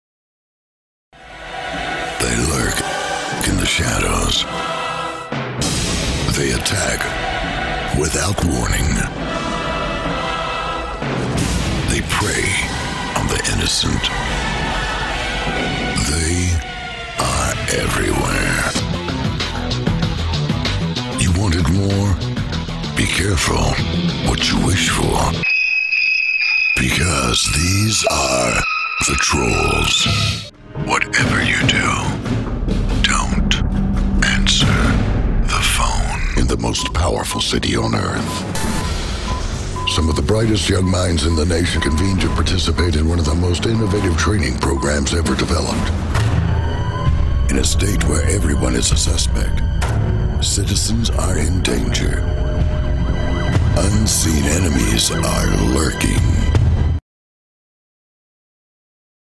Telly, Addy, Summit Creative Award, Cable Advertising Bureau and Associated Press award winning voice talent.